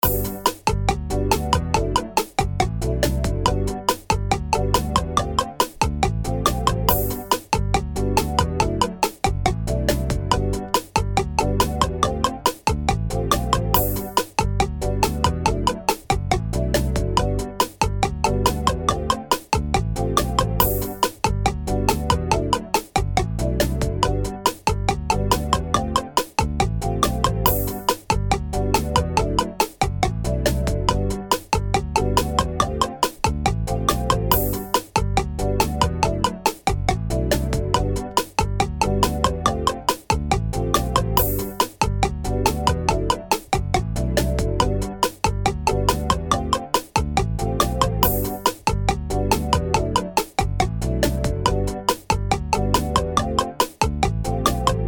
メロディラインがポコポコしている、シンプルで可愛らしいBGMです。
Aは曲の終わり有り。Bはループ対応版です。